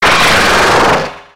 Cri de Zoroark dans Pokémon X et Y.